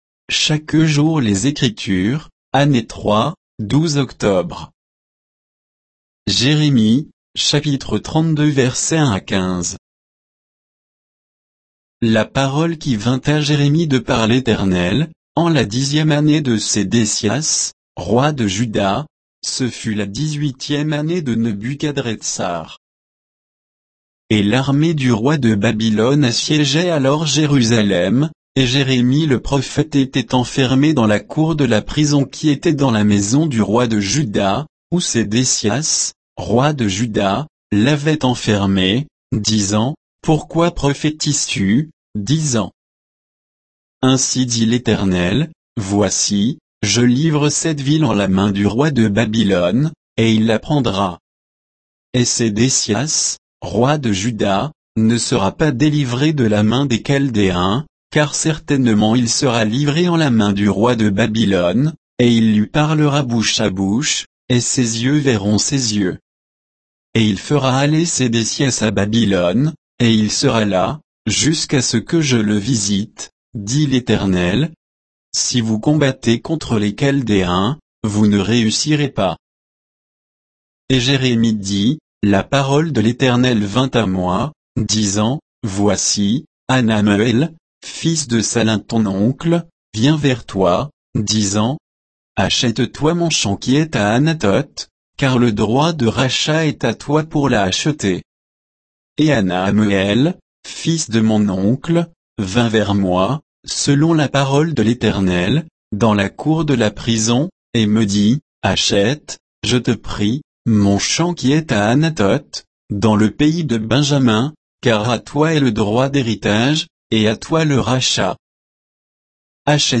Méditation quoditienne de Chaque jour les Écritures sur Jérémie 32